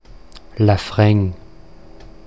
Deutsch-mundartliche Form
[laˈfʀɛŋ]
Laurein_Mundart.mp3